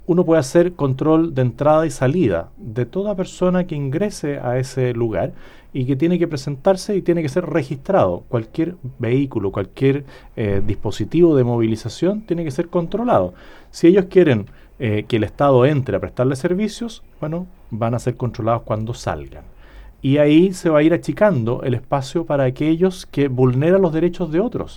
En conversación con La Radio, el abanderado abordó los problemas de violencia en la región, señalando que hay “terrorismo” y que, por lo mismo, la gente no puede dormir en paz y tampoco cosechar sus campos.